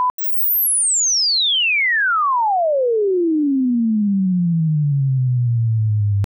PallasPlus Chirp Test
Stimulus.wav